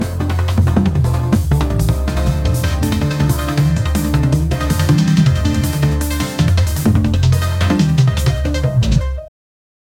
time-goes-fast-things-are-g4kd5kfn.wav